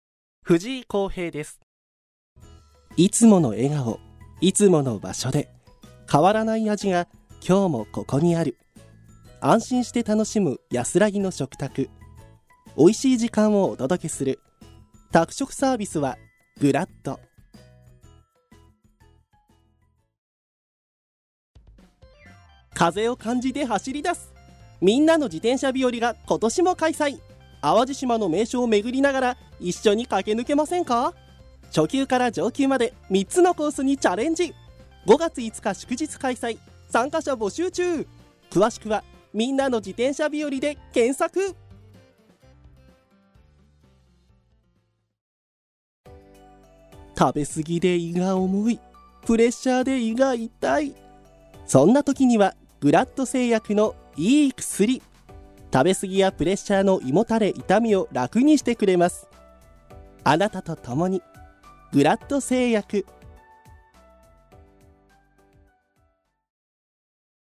ボイスサンプル
• 明るく爽やかな声
• 音域：高～中音
• 声の特徴：さわやか、明るい
• CM
爽やか・ソフトな声質